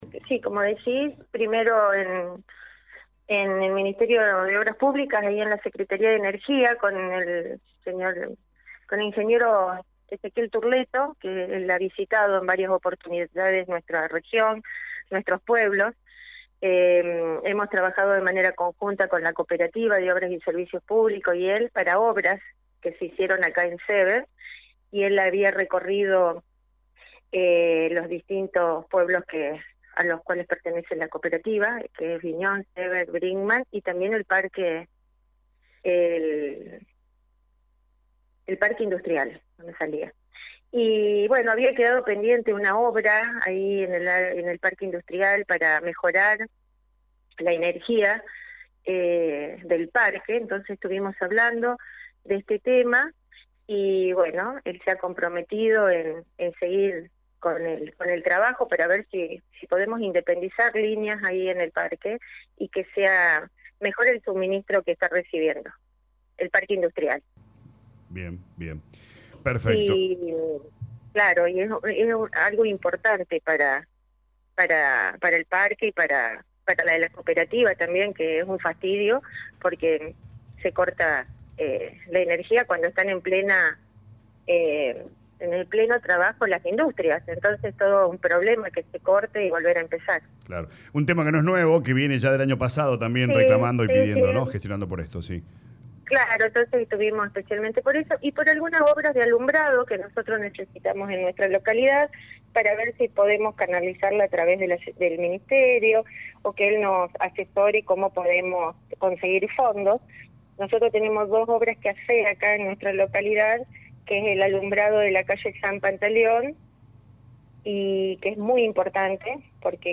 En diálogo con LA RADIO 102.9 FM la intendente de Seeber Celia Giorgis informó que en la jornada del miércoles realizó distintas gestiones en la ciudad de Córdoba.